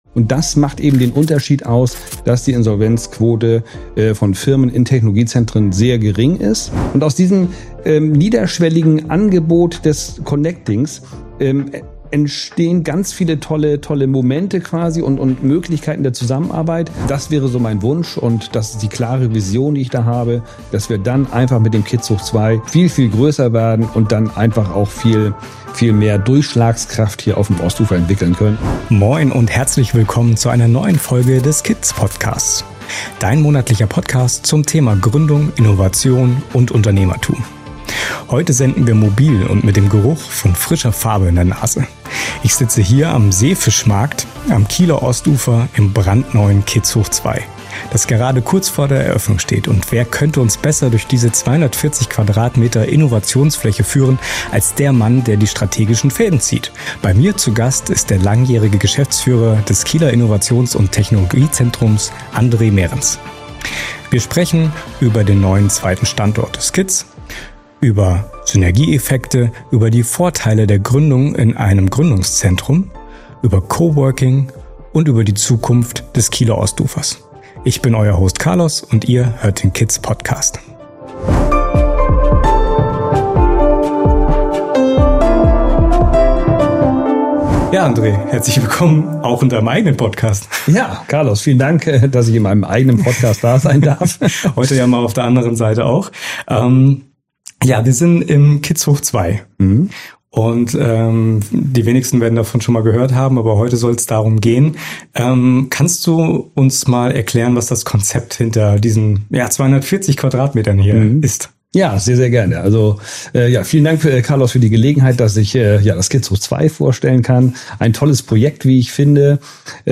Wir senden direkt aus dem frisch eröffneten KITZ² (KITZ Hoch 2) an der Wischhofstraße.